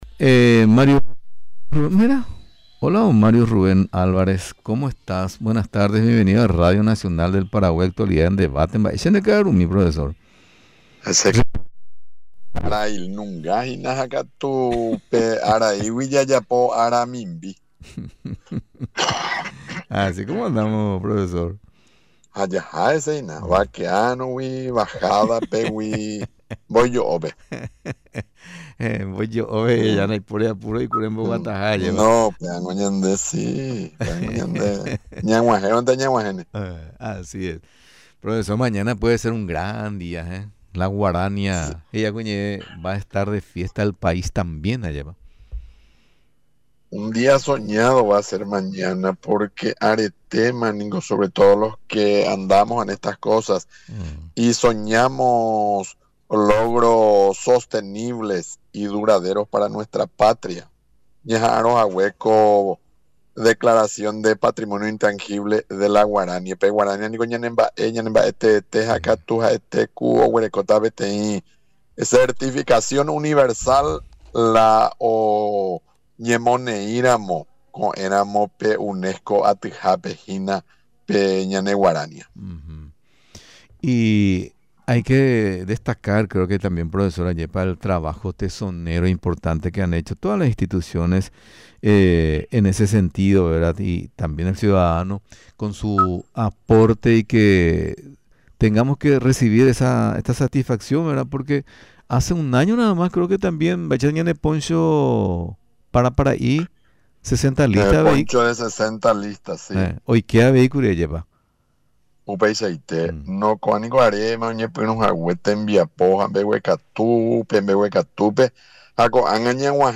Durante la entrevista en Radio Nacional del Paraguay, destacó la importancia del reconocimiento que la UNESCO dará a este género musical, evento previsto con gran expectativa para mañana martes.